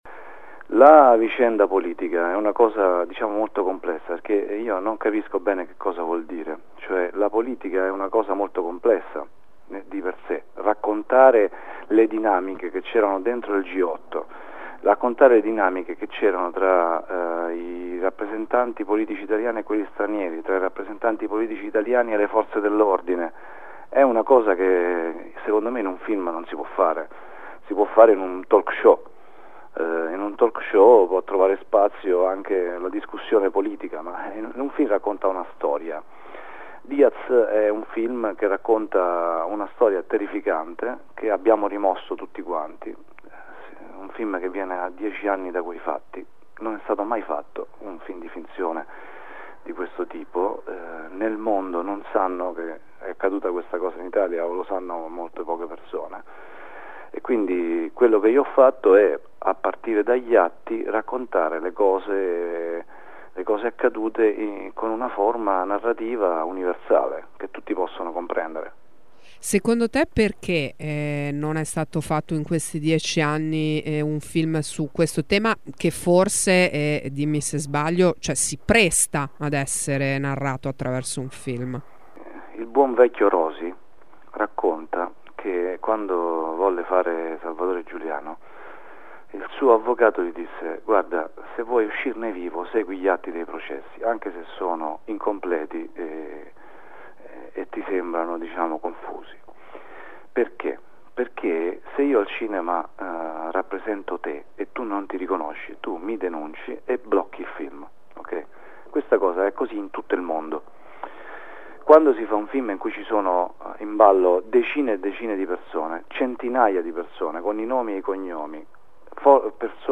Ascolta il regista Daniele Vicari